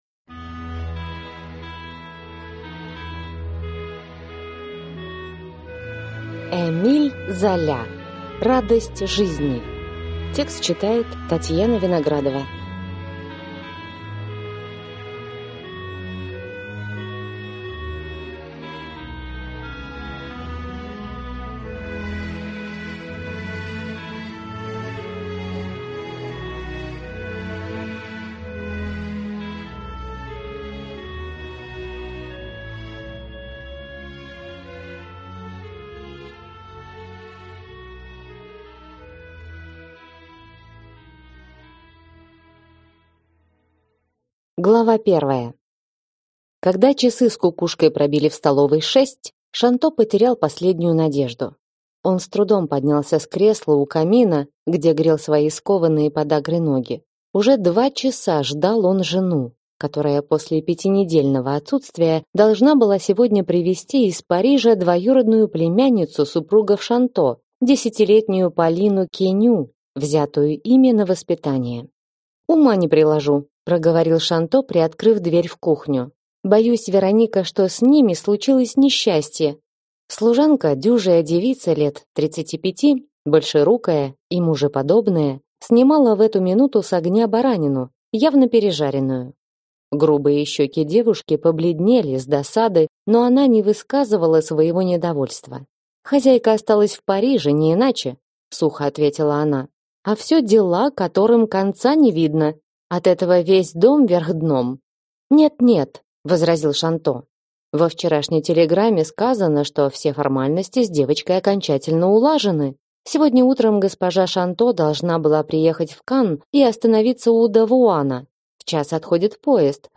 Аудиокнига Радость жизни | Библиотека аудиокниг